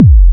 drum21.mp3